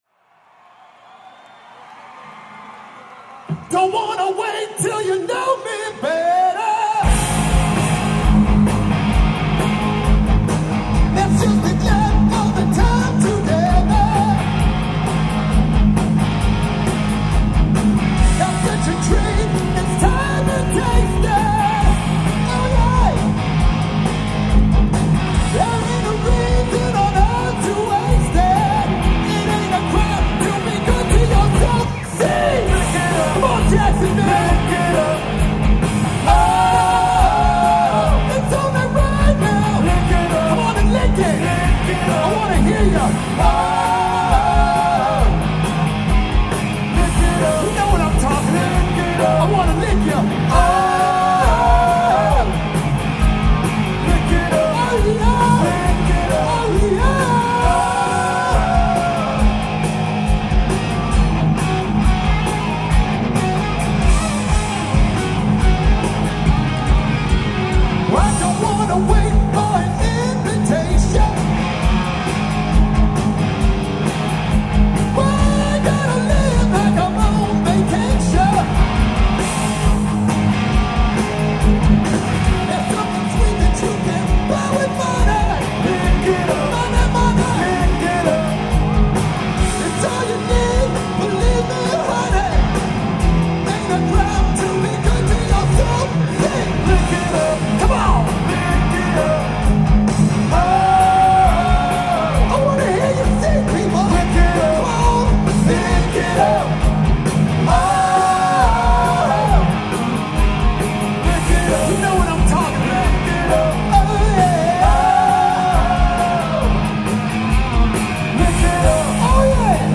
Veterans Memorial Arena
Source: Audience DAT Master